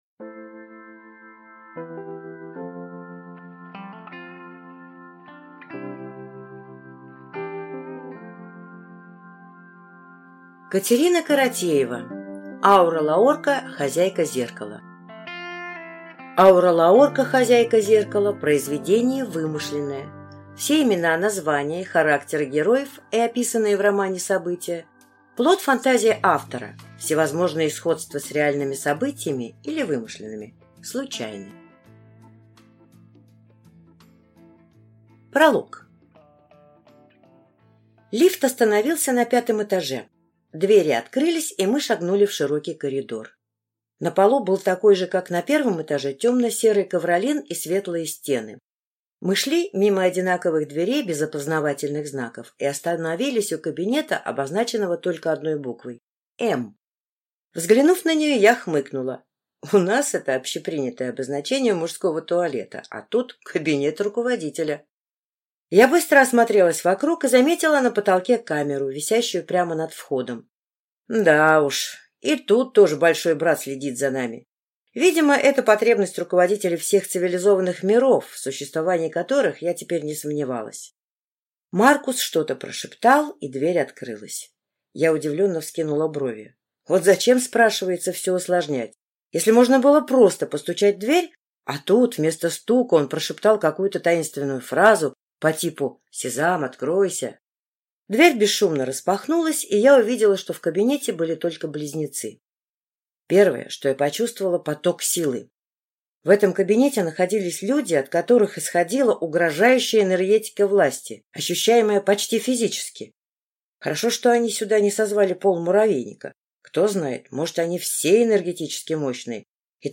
Аудиокнига Аура Лаорка. Хозяйка Зеркала | Библиотека аудиокниг